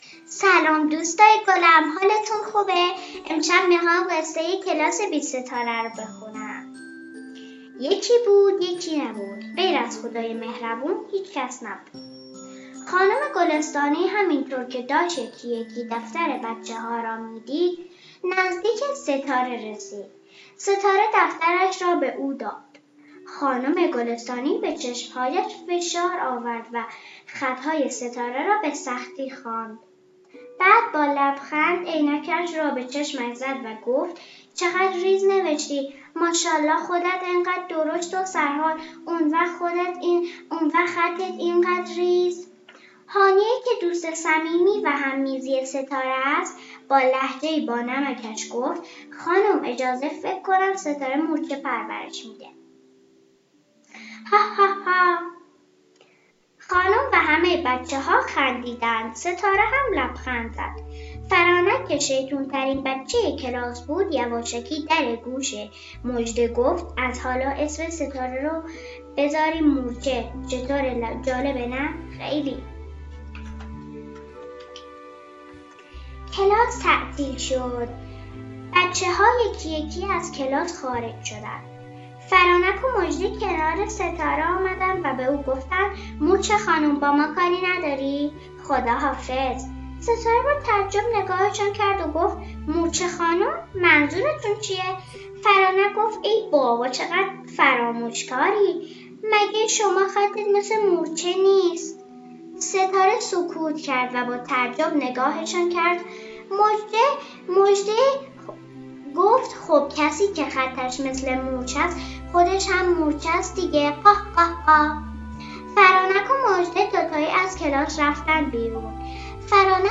قصه صوتی